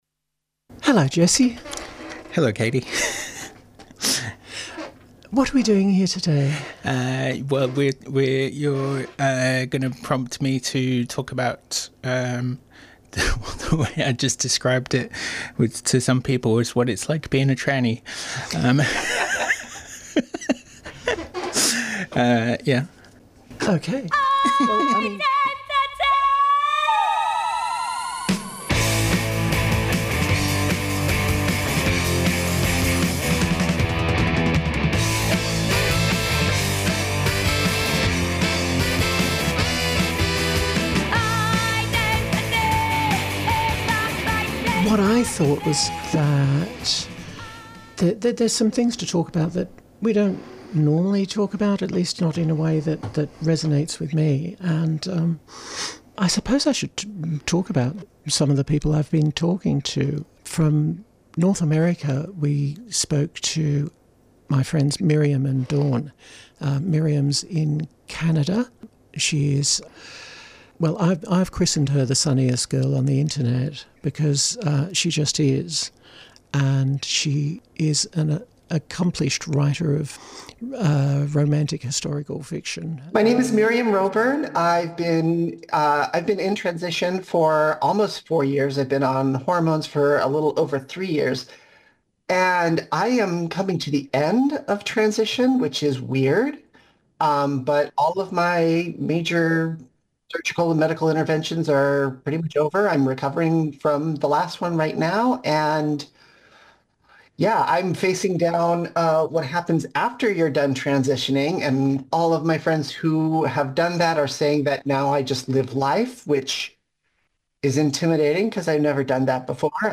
3CR did a remarkable job getting their Trans Day of Audibility programming online by the next morning.
This time I had a week to go through nearly four hours from three recording sessions, find the gems, and string them together in a way that was narratively satisfying.